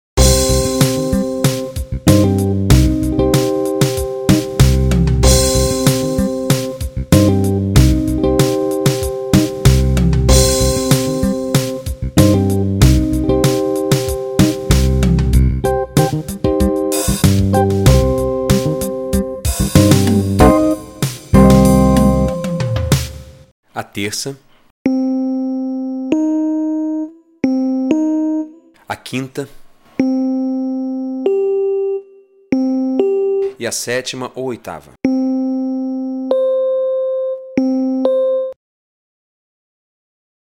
Você vai ouvir o acorde tocado por uma banda e, em seguida, nó vamos desmembrá-lo nas notas que o compôe.